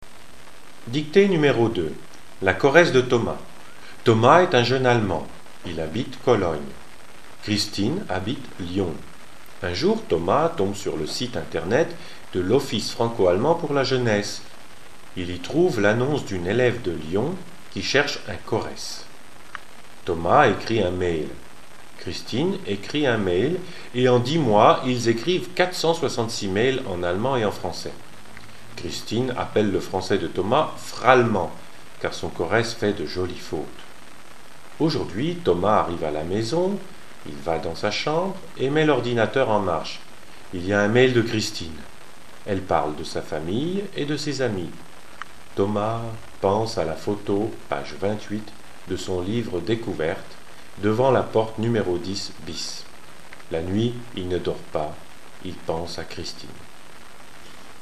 dictee-2a.mp3